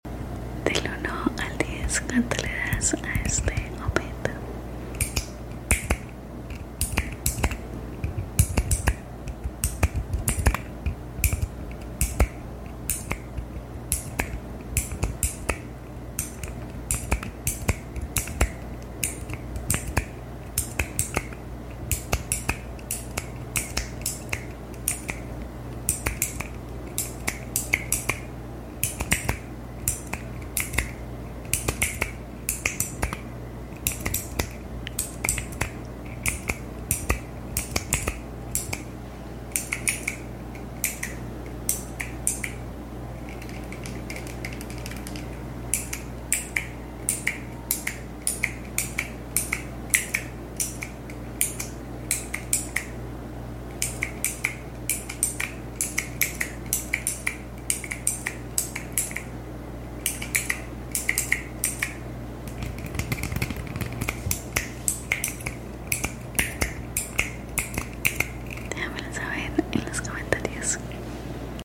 ⌨✨🫠😴 TECLAS SOUND🔴 ÚNETE al sound effects free download